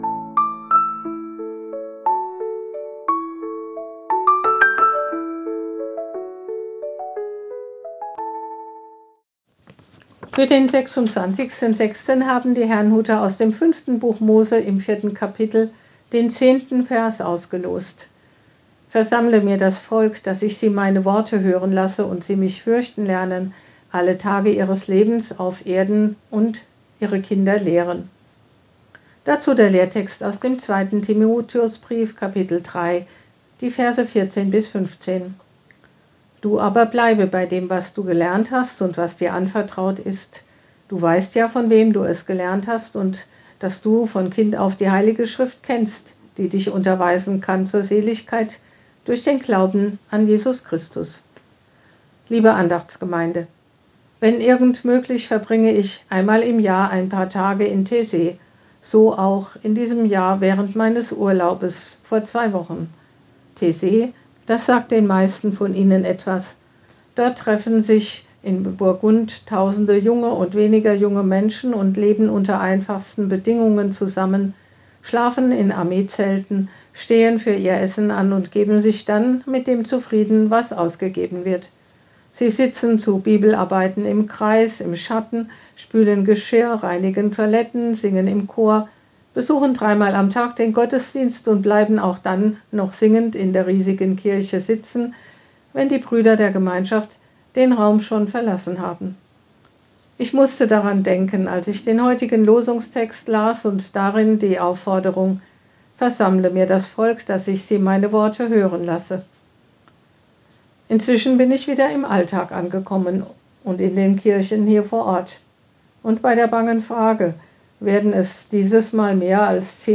Losungsandacht für Donnerstag, 26.06.2025
Text und Sprecherin